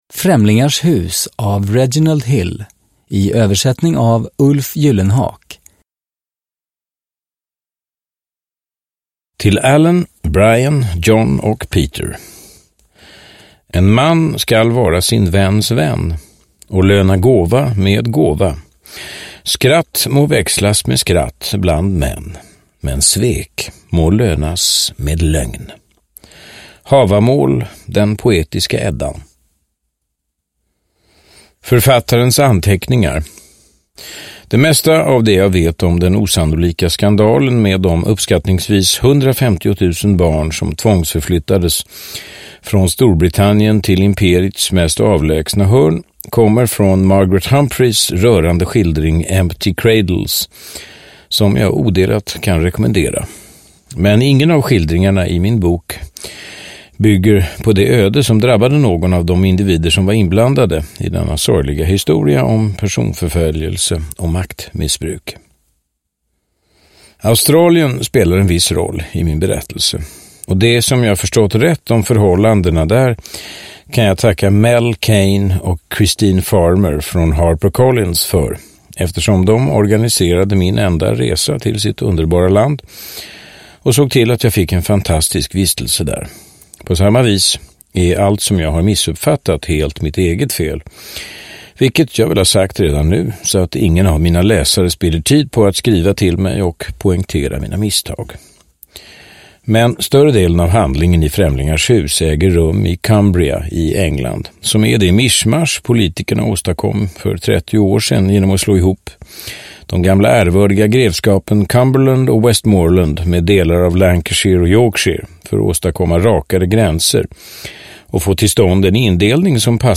Främlingars hus – Ljudbok – Laddas ner
Uppläsare: Johan Rabaeus